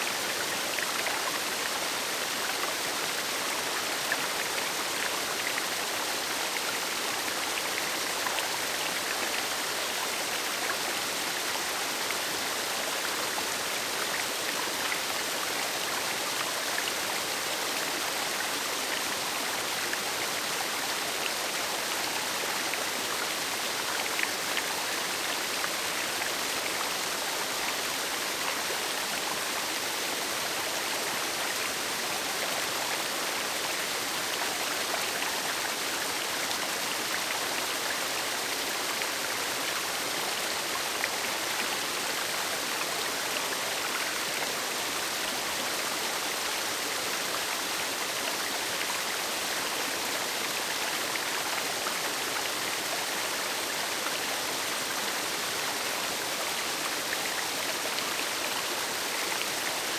Waterfalls Rivers and Streams
River Loop.wav